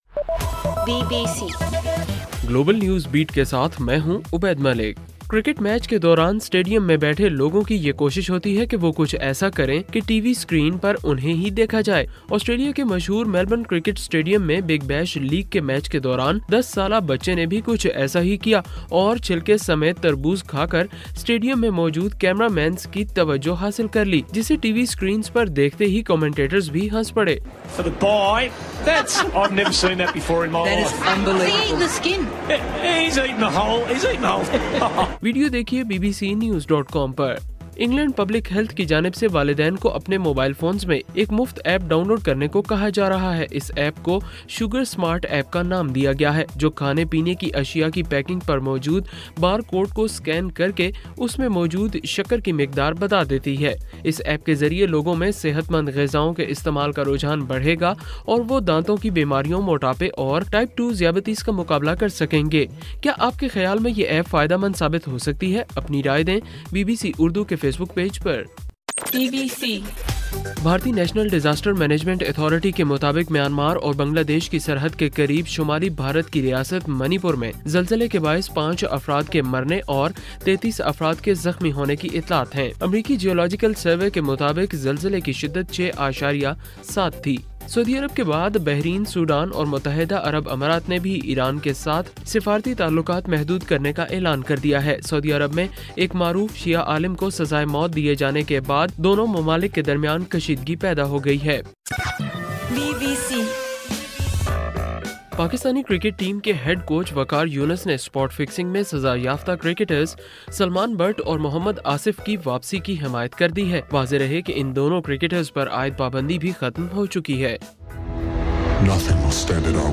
جنوری 05:صبح 1 بجے کا گلوبل نیوز بیٹ بُلیٹن